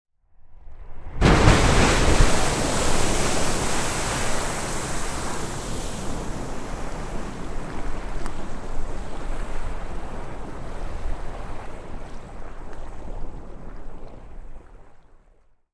Звуки гейзеров
Гейзер – добавим этот еще, и пожалуй хватит